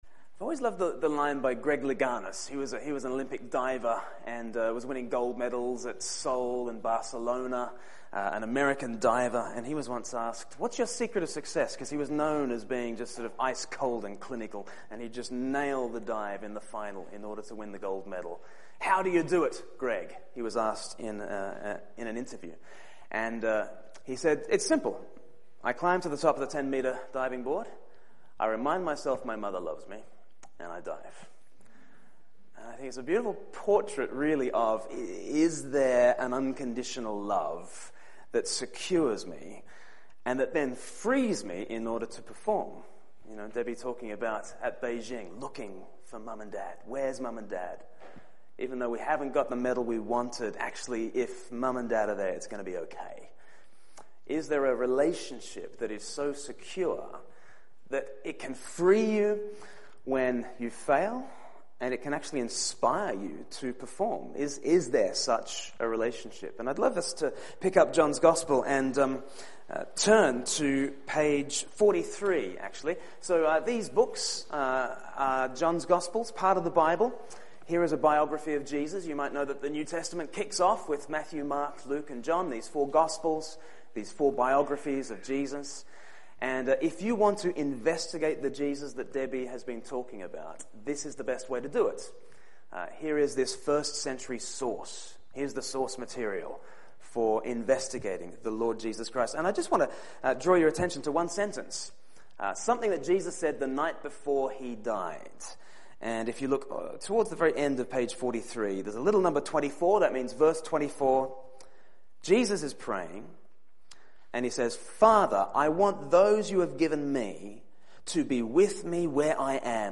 Seminar
Interview with Debbie Flood